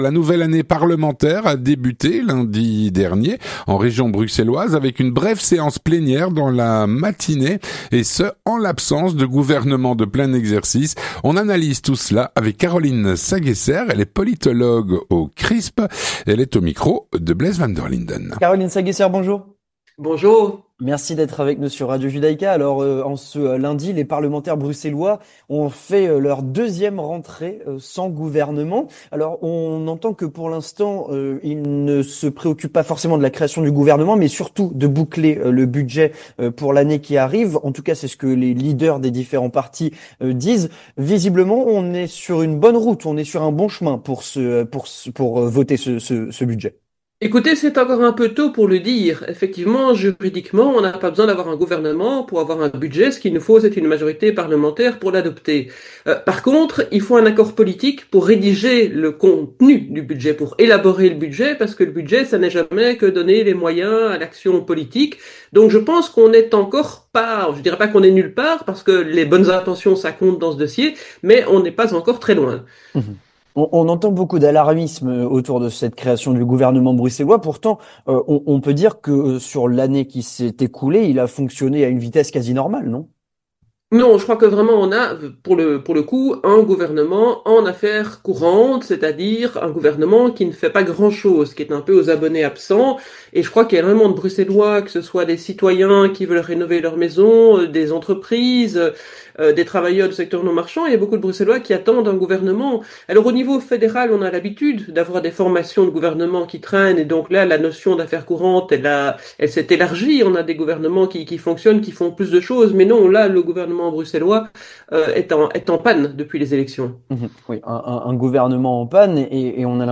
L'entretien du 18H - Rentrée parlementaire bruxelloise sans nouveau gouvernement.